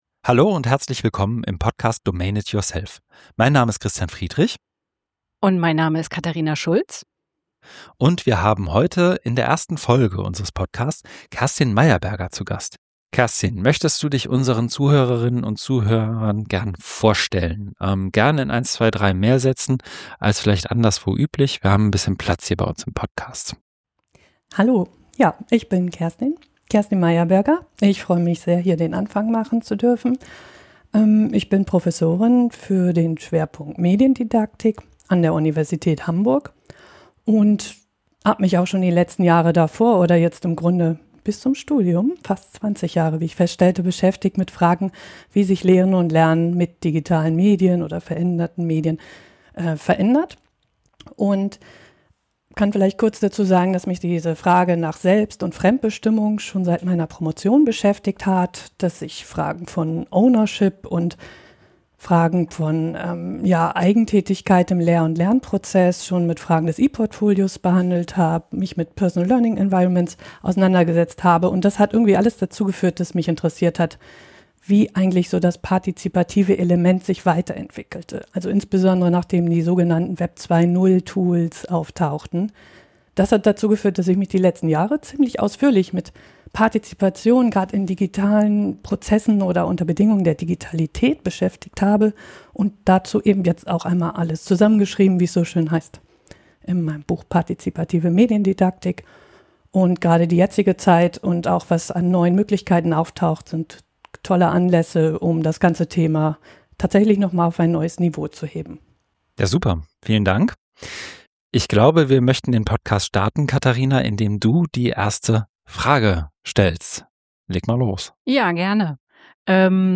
im Gespräch zu Partizipation, Mediendidaktik und Domain of One’s Own